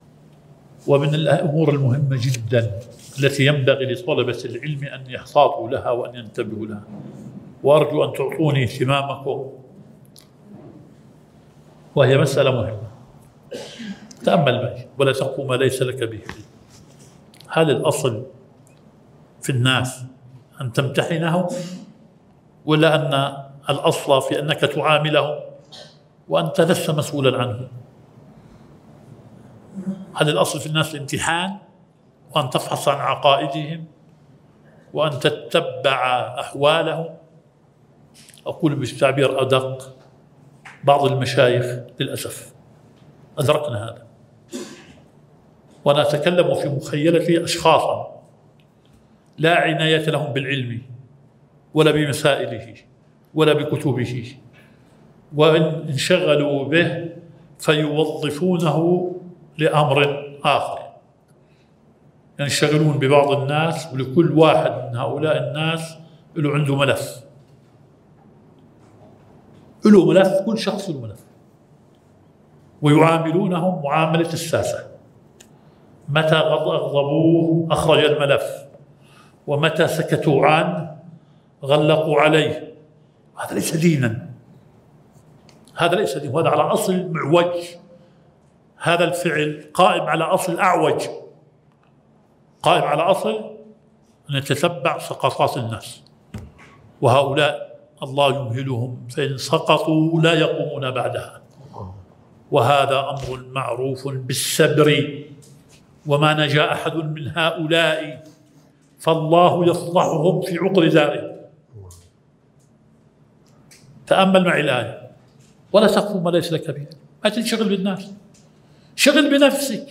المحاضرة الثانية